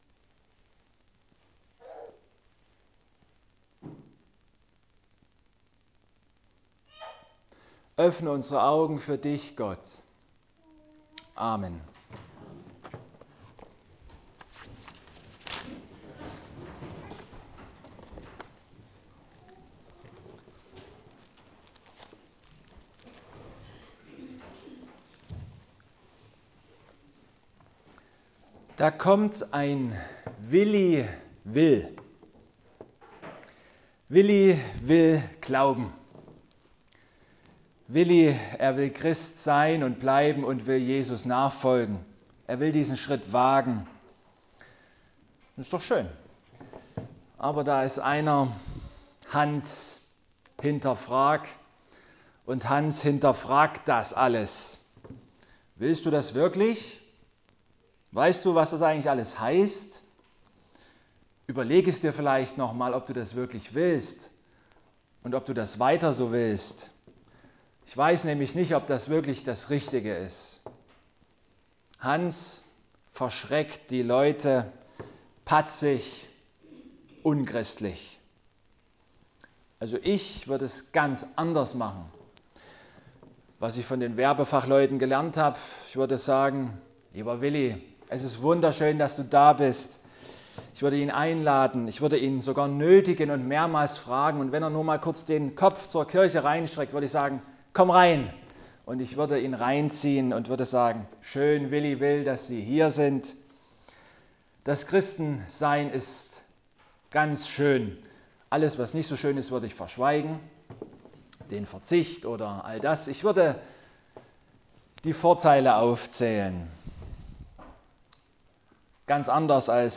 Lukas 9,57-62 Gottesdienstart: Abendmahlsgottesdienst Wenn ich Werbefachmann wäre